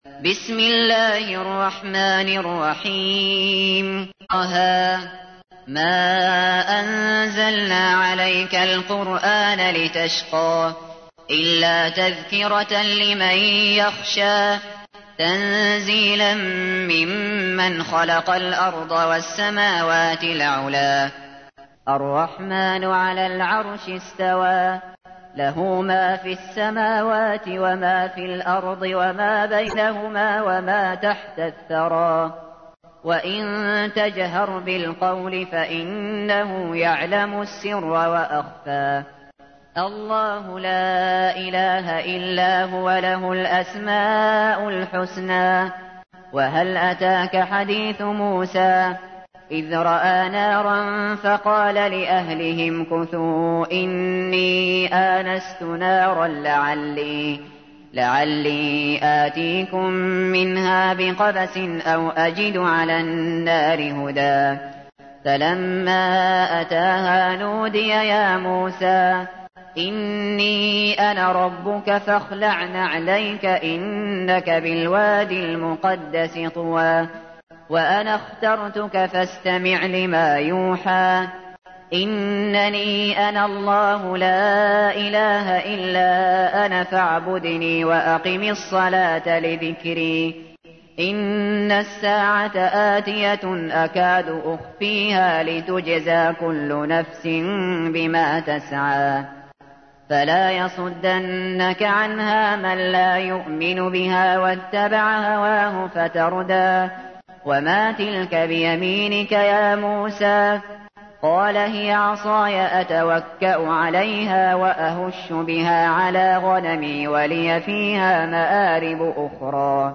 تحميل : 20. سورة طه / القارئ الشاطري / القرآن الكريم / موقع يا حسين